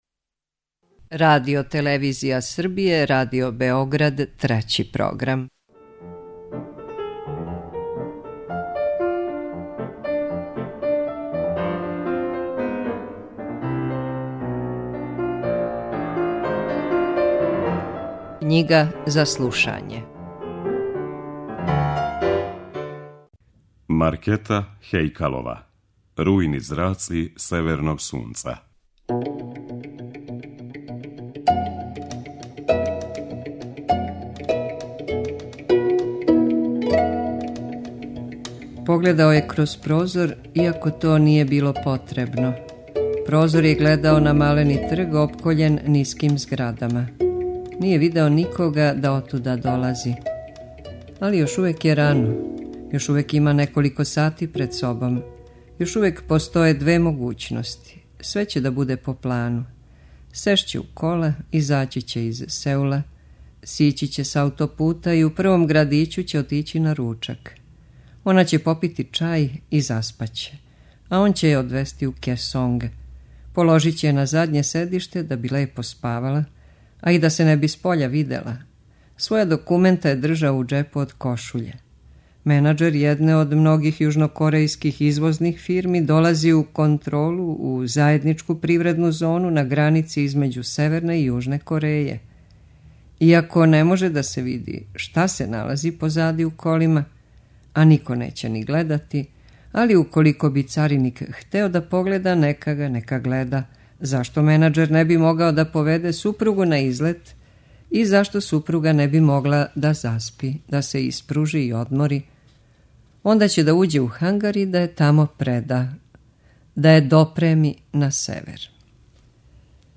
Књига за слушање